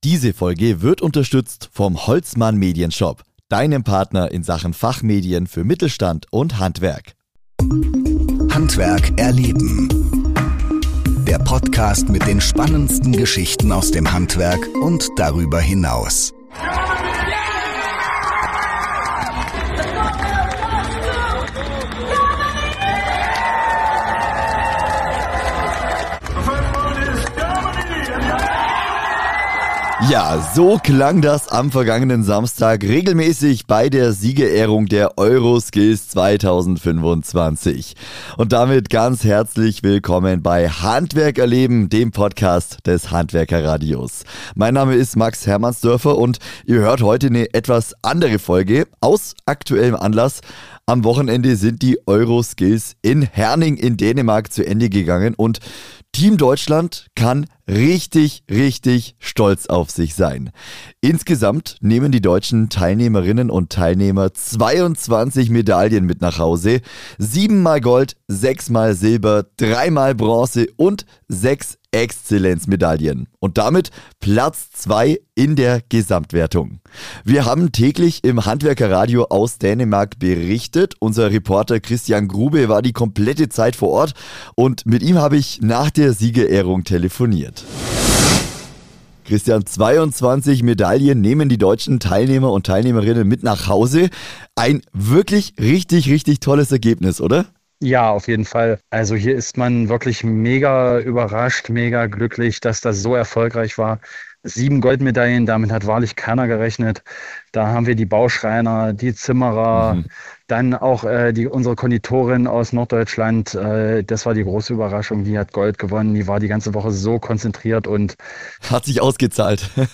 In dieser Folge hört ihr Interviews, Gewinner-O-Töne und Statements aus der Politik zu den EuroSkills 2025.